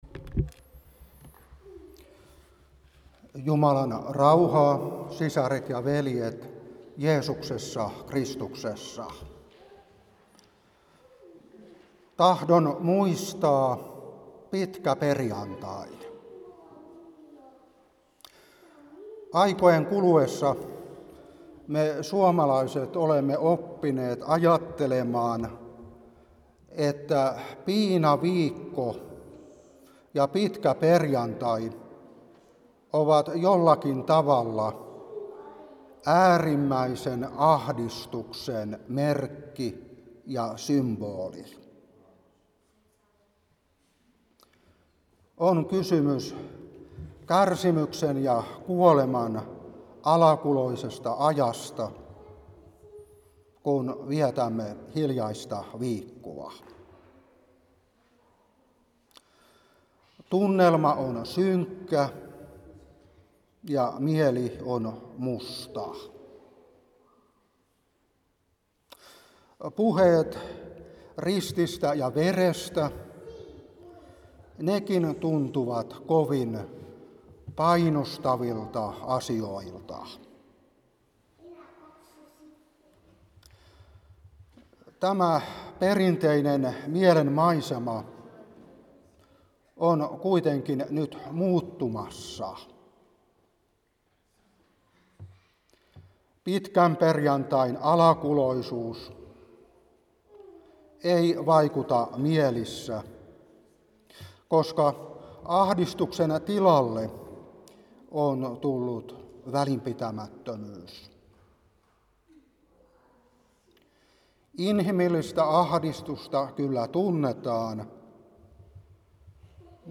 Opetuspuhe 2025-4. 1.Kor.2:1-5. Room.6:3-8.